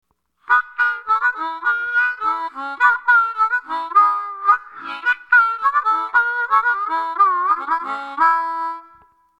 However, it's a very clean sound compared to the Bottle o Blues, so it doesn't suit everyone. I used my amp effects to muddy it a bit.
Shaker Madcat & Lee Oskar, Eggstatic Mic with Lee Oscar, Bottle O Blues mic with Lee Oskar .
18.shaker_madcat_mic_leeosc.mp3